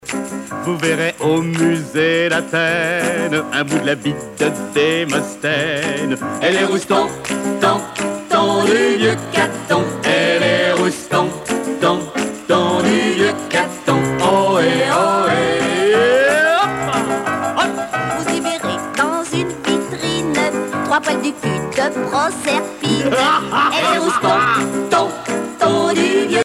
Catégorie Pièce musicale éditée